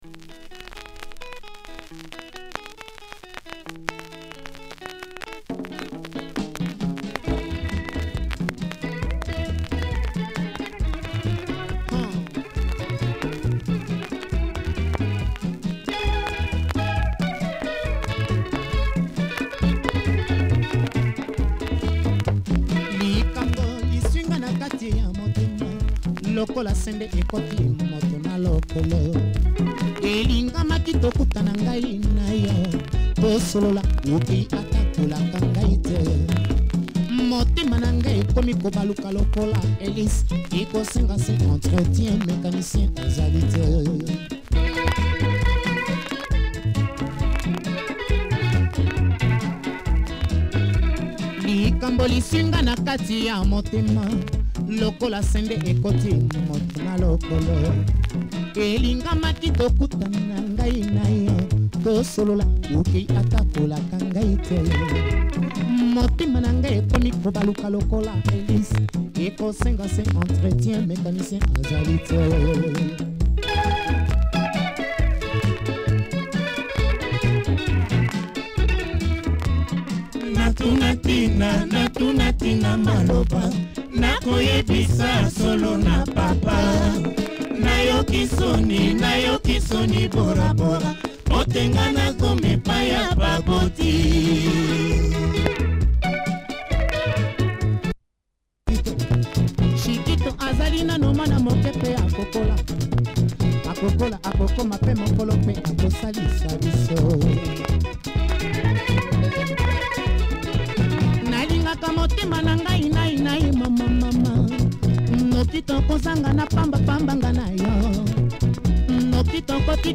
Sassy modern Lingala number
great vocals too